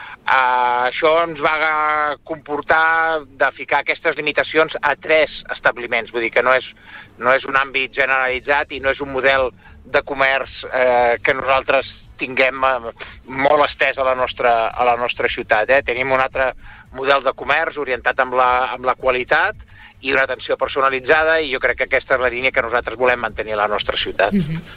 D’aquesta manera es pretén evitar la molèstia als veïns que causaven els comerços, en especial relacionats amb la venda d’alcohol. Ho explica l’alcalde de Sant Feliu de Guíxols, Carles Motas.